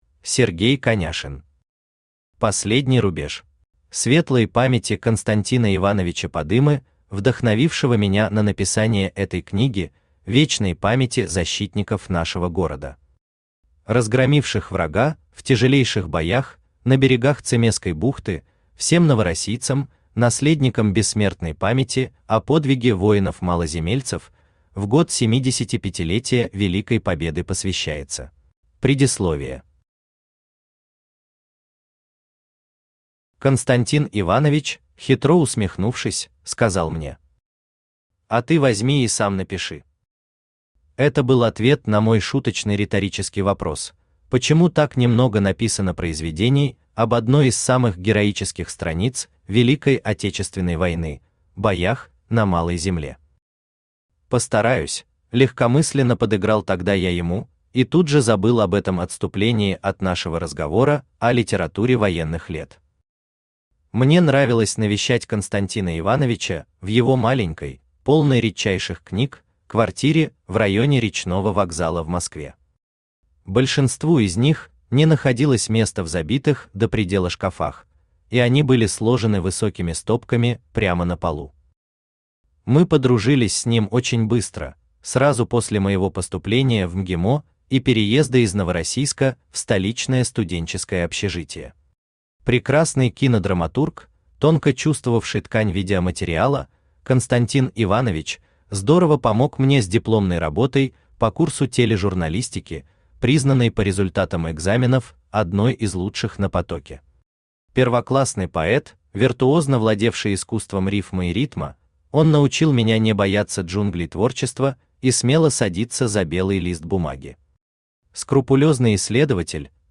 Аудиокнига Последний рубеж | Библиотека аудиокниг
Aудиокнига Последний рубеж Автор Сергей Сергеевич Коняшин Читает аудиокнигу Авточтец ЛитРес.